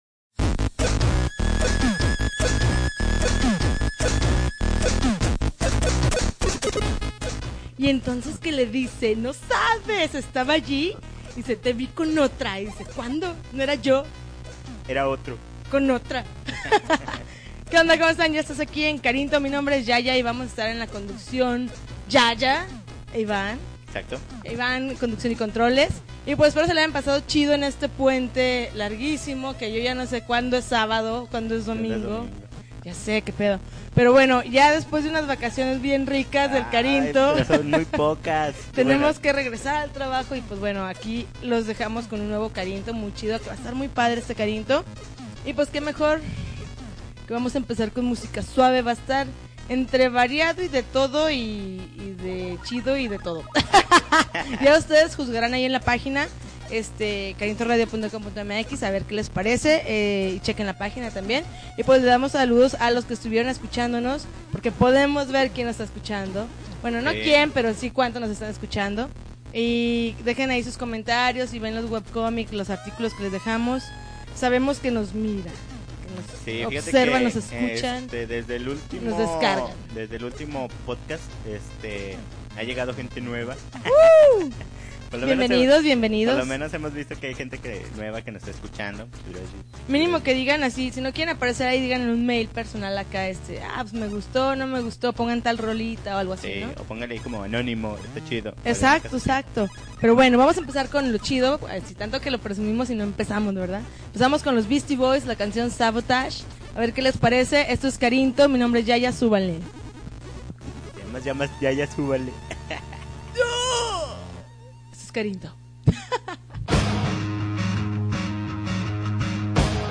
September 19, 2010Podcast, Punk Rock Alternativo